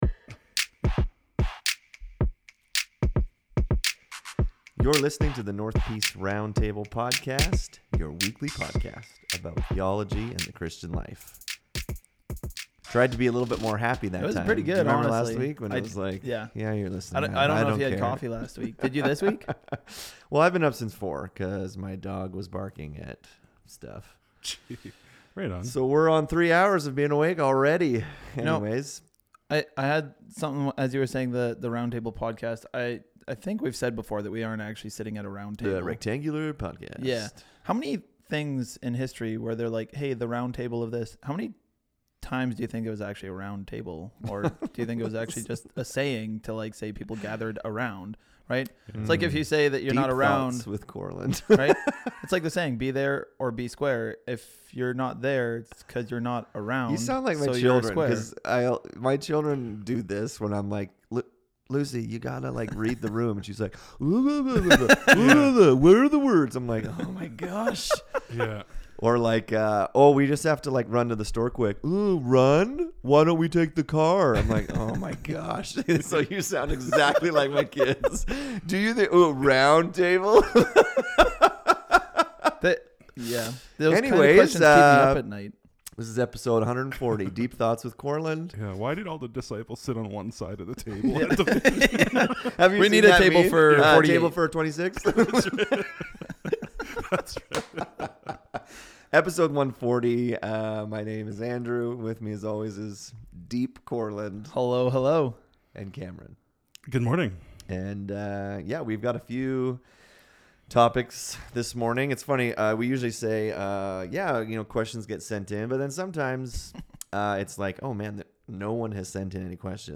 In this episode the guys discuss two topics. First off, how should we handle and approach Christian media?